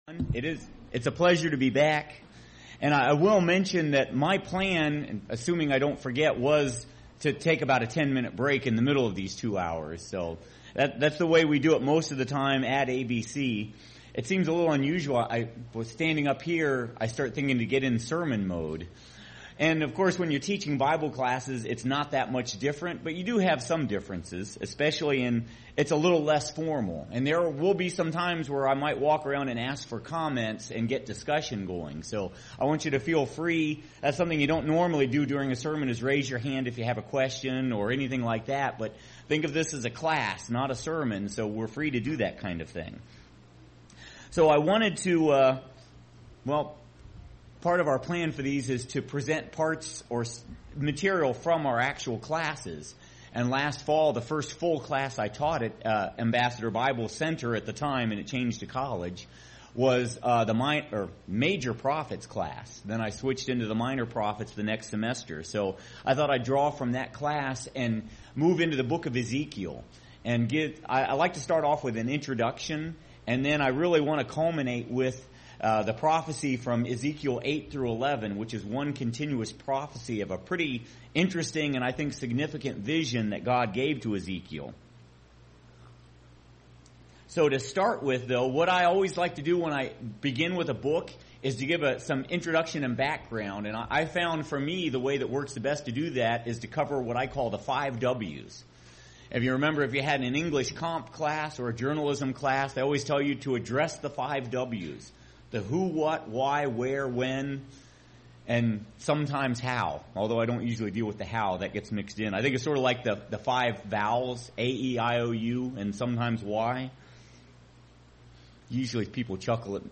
ABC Continuing Education Sampler